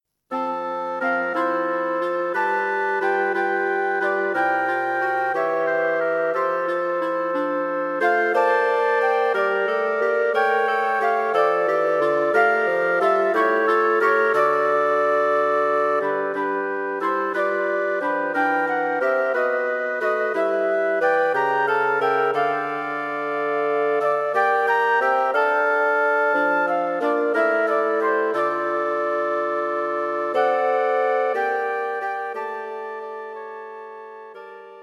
Wind Quartet for Concert performance
A simple melody.